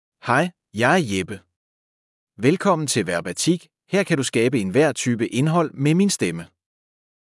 MaleDanish (Denmark)
Jeppe — Male Danish KI-Stimme
Stimmprobe
Hören Sie Jeppes male Danish-Stimme.
Male